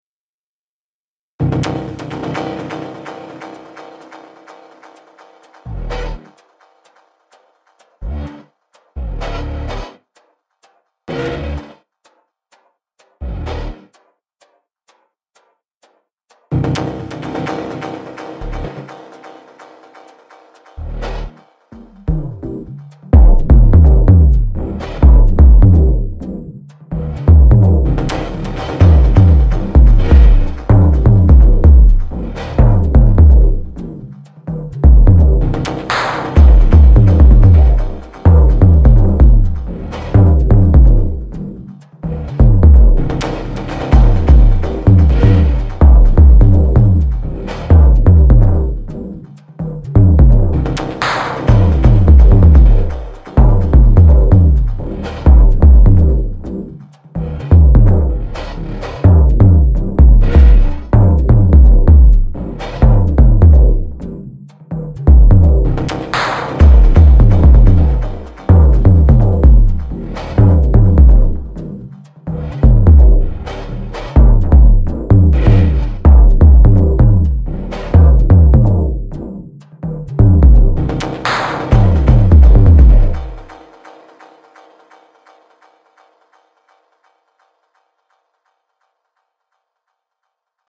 Мне нравится как он добавляет звуку верха и детальности и вообще корректирует микс.
Заменил мне Waves LInMB вот тут на мастере 354 плюс лимитер от fab filter.